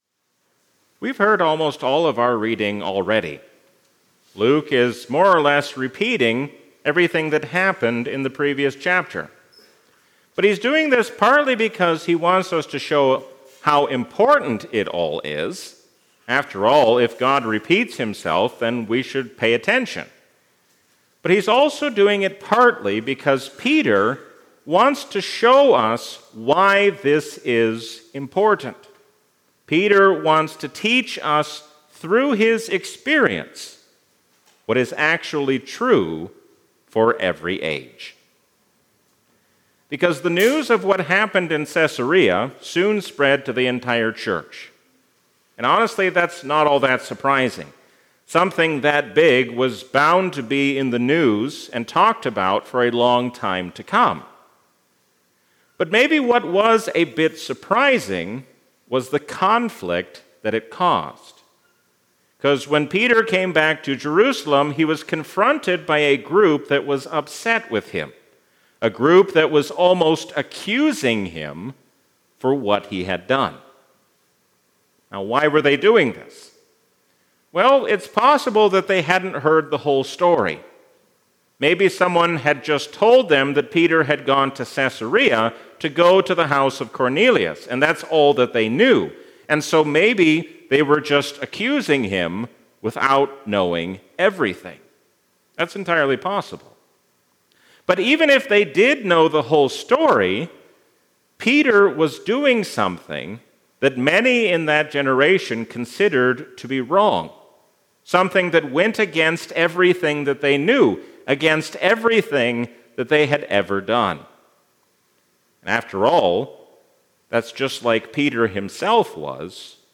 A sermon from the season "Trinity 2025." No matter how much the world changes, we can be confident because Jesus does not change.